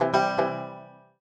banjo_cecgce.ogg